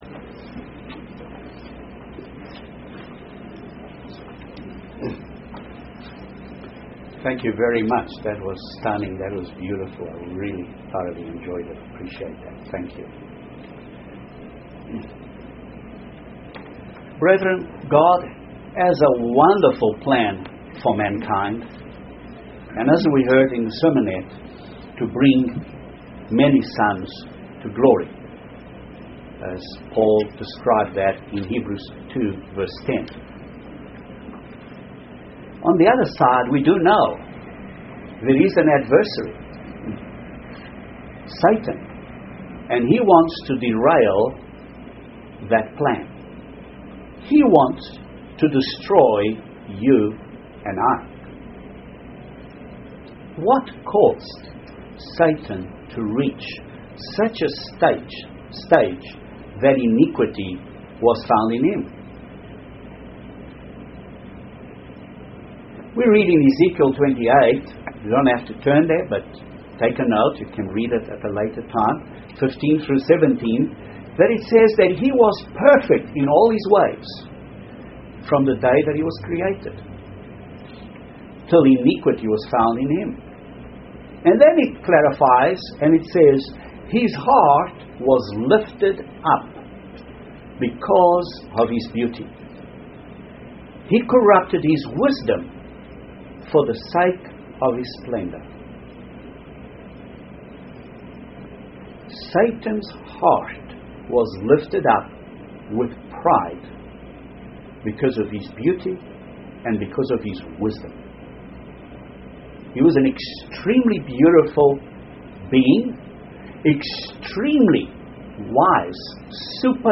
We should take time to examine ourselves and to determine how we are personally growing in these Christian attitudes, before we take the symbols of the bread and wine. This sermon analyses four of these attitudes. Furthermore it demonstrates how these attitudes are diametrically opposite to Satan's mind set.